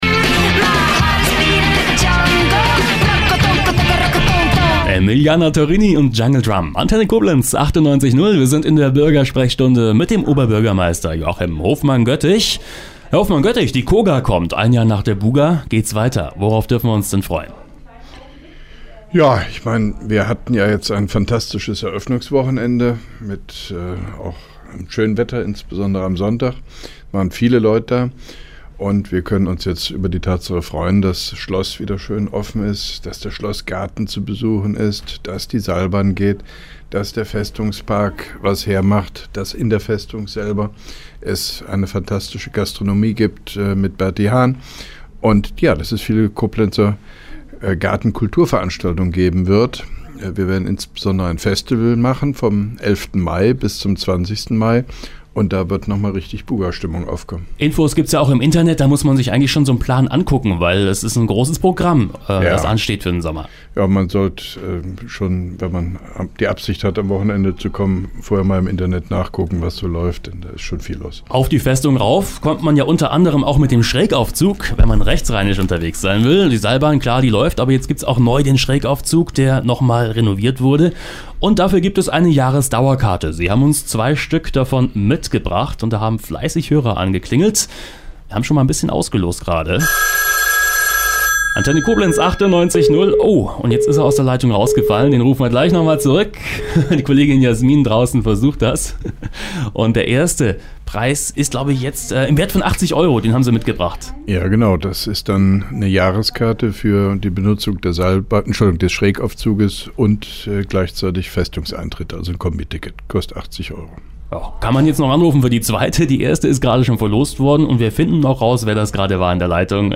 (3) Koblenzer Radio-Bürgersprechstunde mit OB Hofmann-Göttig 03.04.2012